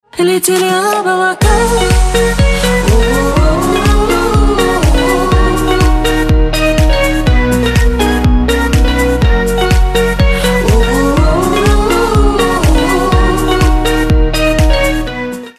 • Качество: 128, Stereo
позитивные
dance
Electronic
позитивная музыка